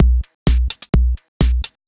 Electrohouse Loop 128 BPM (20).wav